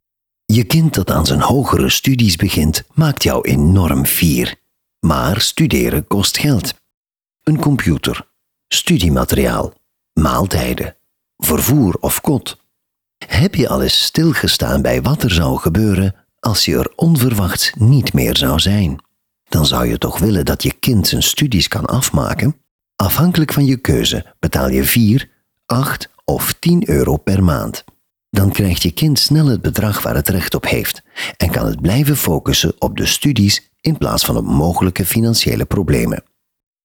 Distinctive, Mature, Warm
Corporate